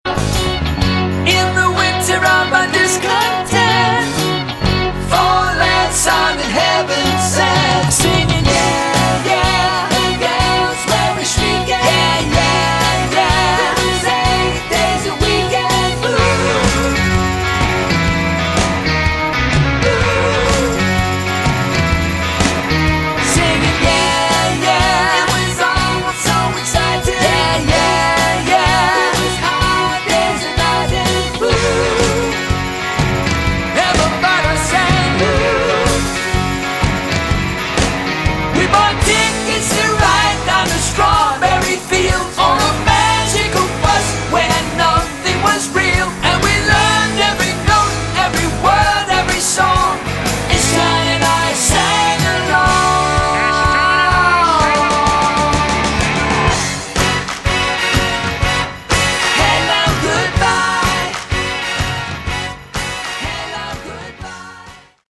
Category: AOR
lead and backing vocals, keyboards, synth bass
guitar, bass, backing vocals